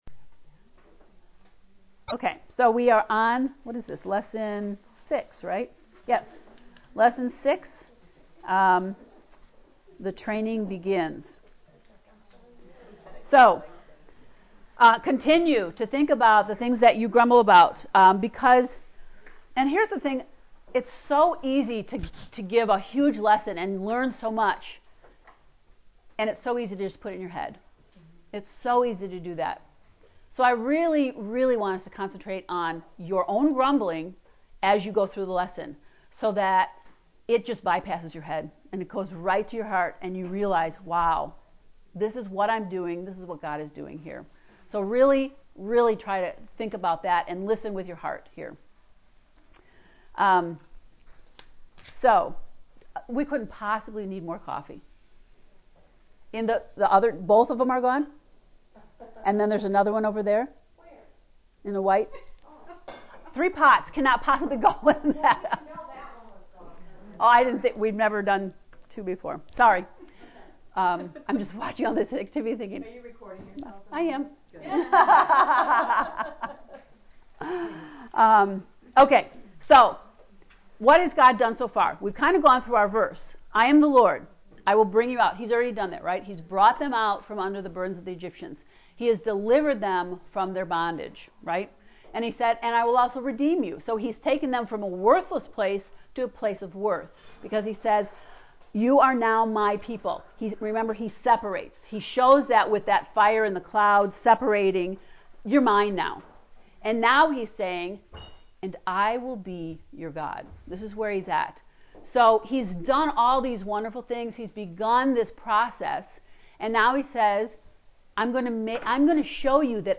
To listen to lesson 6 lecture “The Training Begins” click on link below: